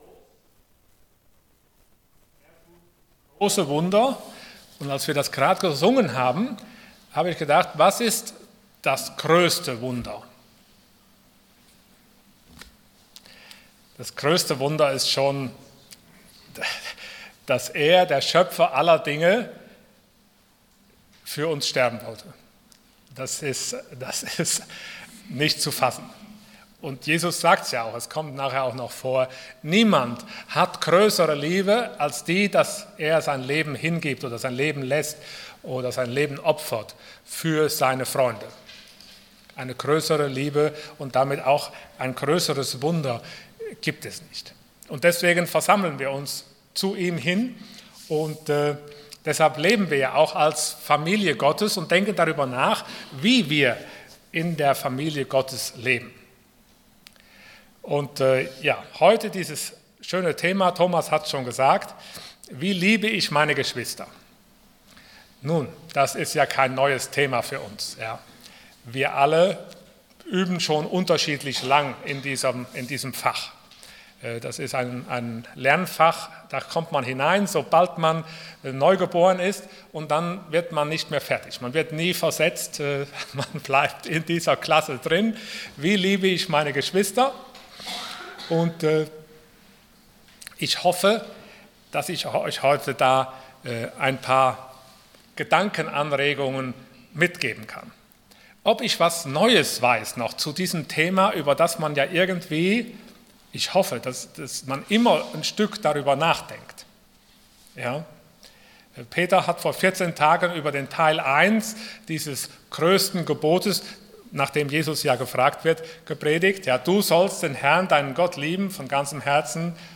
Serie: Wie lebe ich in Gottes Familie? Dienstart: Sonntag Morgen Wie können wir unsere Geschwister lieben?